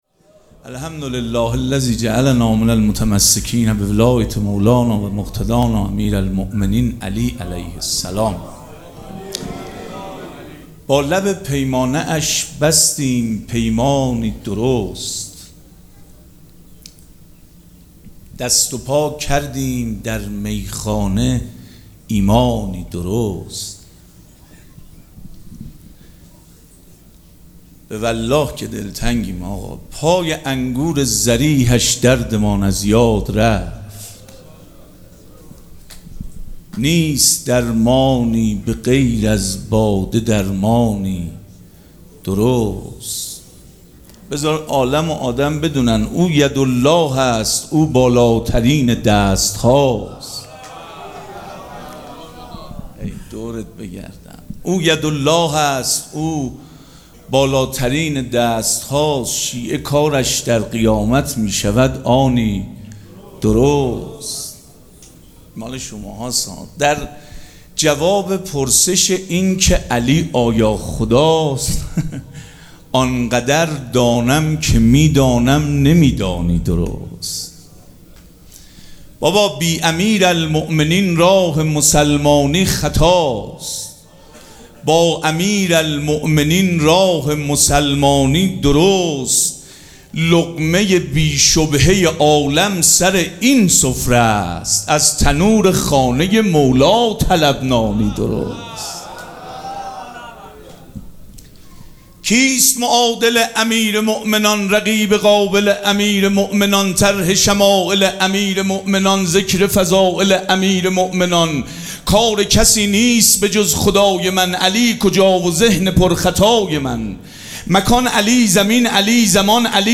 مراسم جشن ولادت حضرت زینب سلام‌الله‌علیها
شعر خوانی
مداح